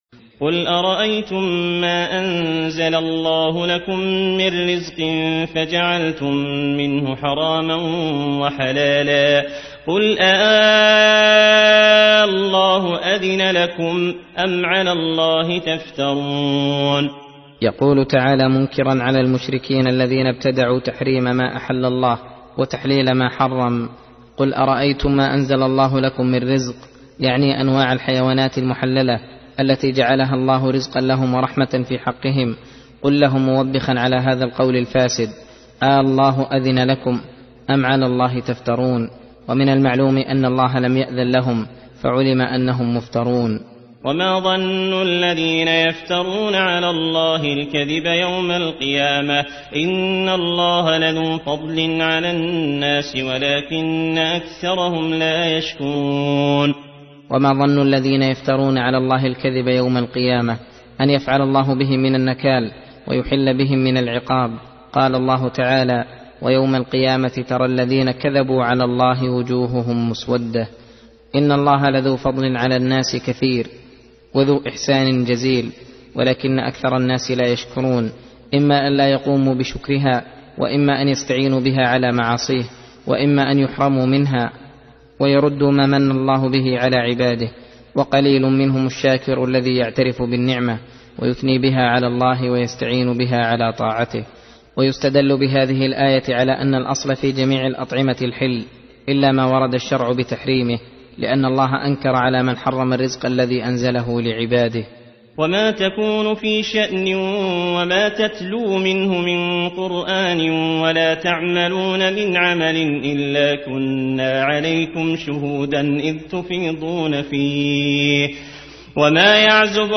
درس (28) : تفسير سورة يونس : (59 - 78)